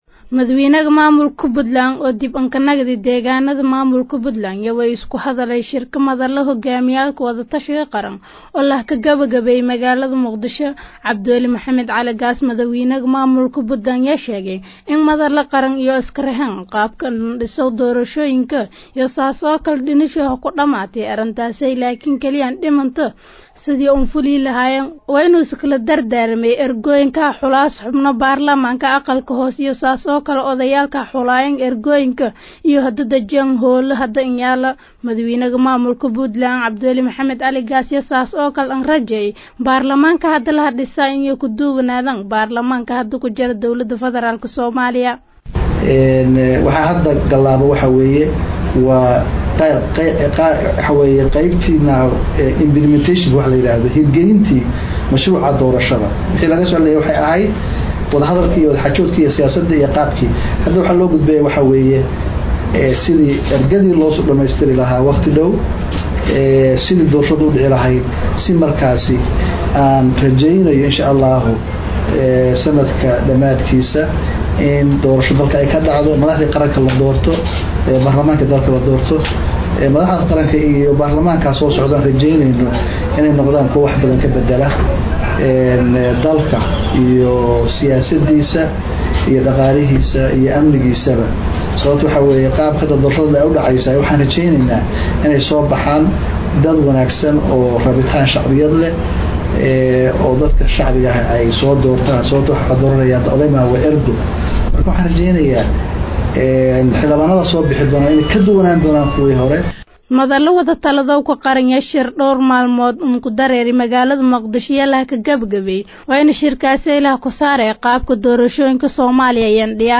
Dhageyso:Madaxweynaha Maamulka Puntland ayaa ka hadlay Arimaha Doorashooyinka wadanka ka dhacaya bilaha soo socda